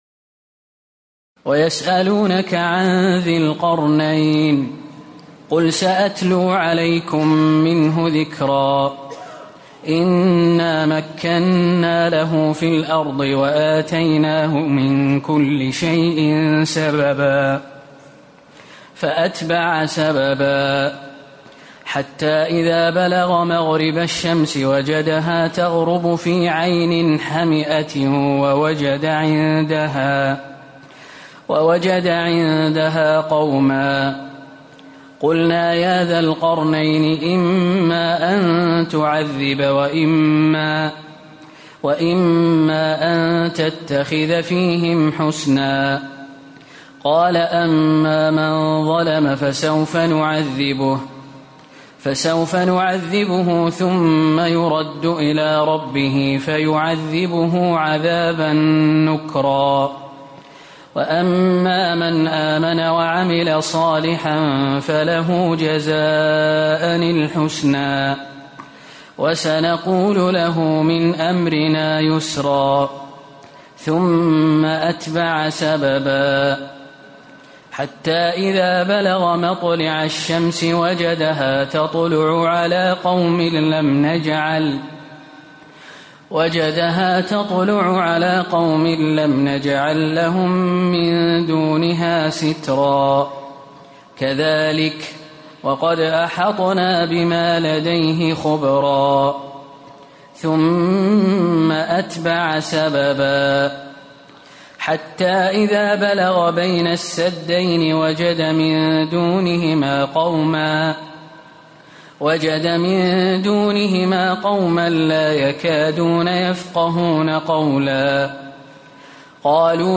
تراويح الليلة الخامسة عشر رمضان 1437هـ من سور الكهف (83-110) و مريم كاملة Taraweeh 15 st night Ramadan 1437H from Surah Al-Kahf and Maryam > تراويح الحرم النبوي عام 1437 🕌 > التراويح - تلاوات الحرمين